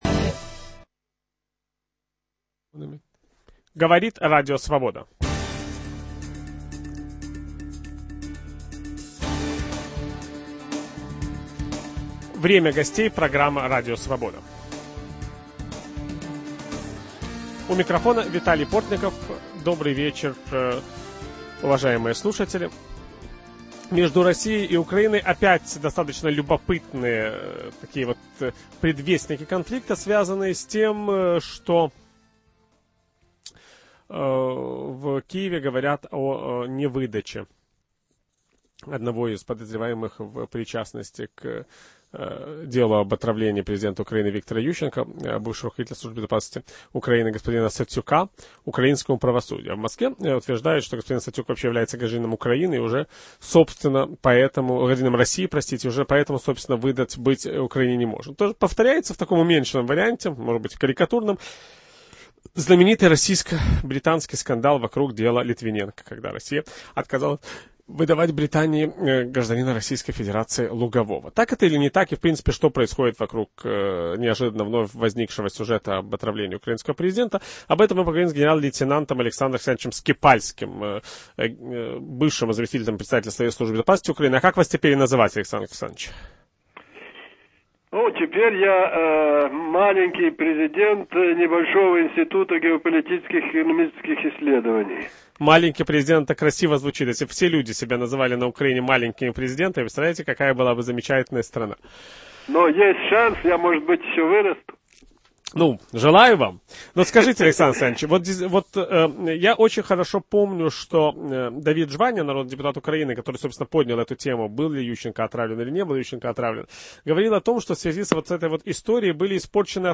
Что происходит вокруг дела об отравлении Виктора Ющенко? В гостях у Виталия Портникова генерал-лейтенант Службы безопастности Украины Александр Скипальский.